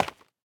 Minecraft Version Minecraft Version snapshot Latest Release | Latest Snapshot snapshot / assets / minecraft / sounds / block / tuff / step1.ogg Compare With Compare With Latest Release | Latest Snapshot
step1.ogg